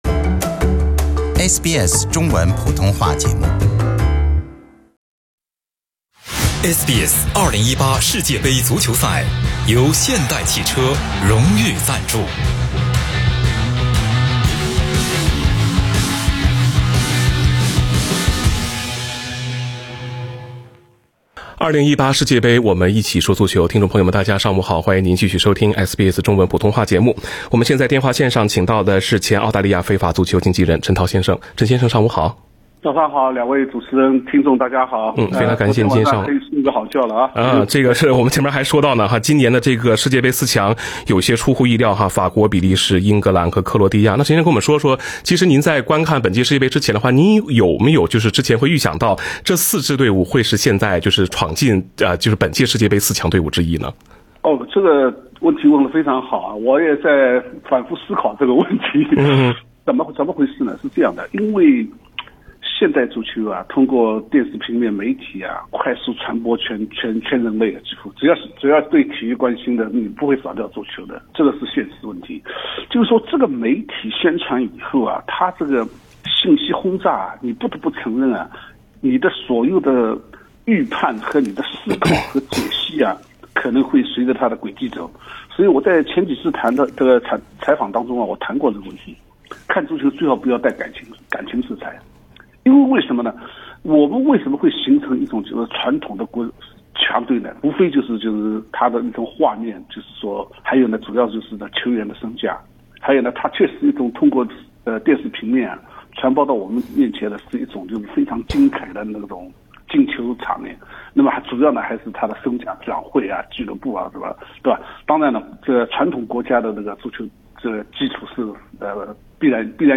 【2018世界杯】 赛事点评：比利时淘汰巴西闯进半决赛，不内讧的欧洲红魔能赢世界杯